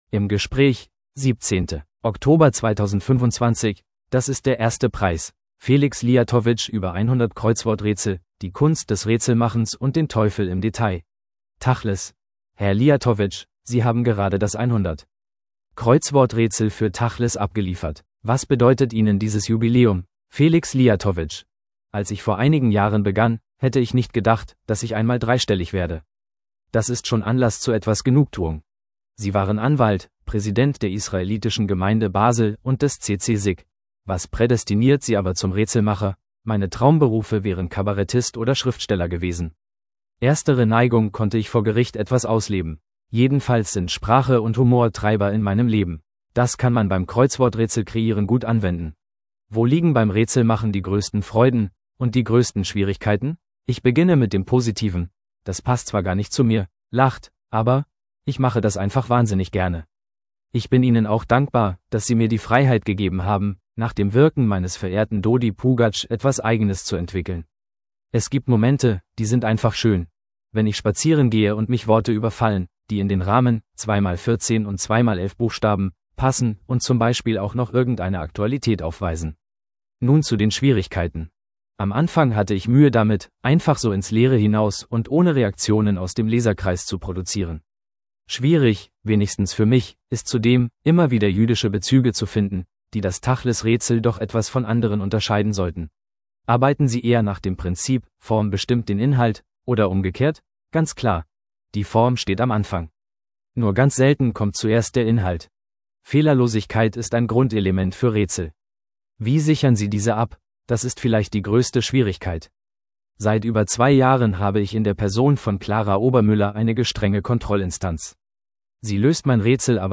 im Gespräch 17.